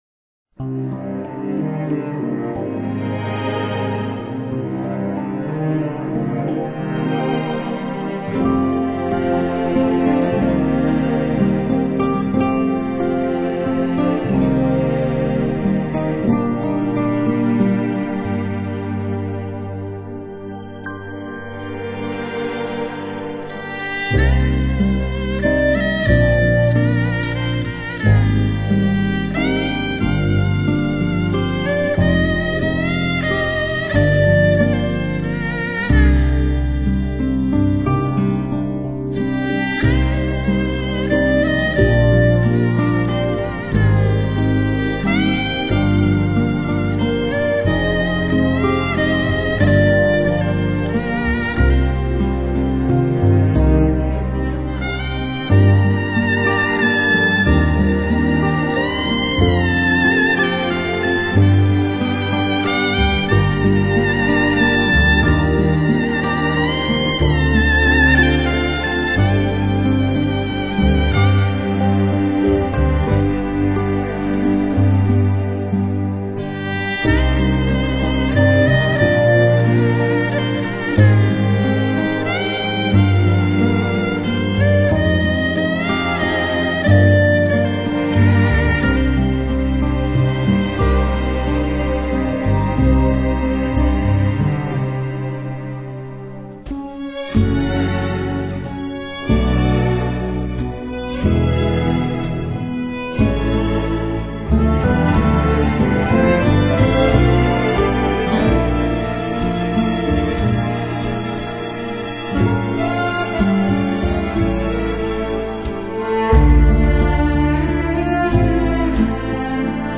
京胡演奏
这张京胡演奏大碟可以说是集音乐性和音响性于一身，
专辑本身对声音的捕捉相当传神，
拉弓在两根弦中间以及京胡琴皮上的运动细腻微妙，
或欢快活跃、或低沉哀怨、或踌躇满志、或委婉动人，
能演奏出象笛子一样连绵不断的乐音，
而且音色比小提琴更接近于人声。